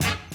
HORN HIT 3.wav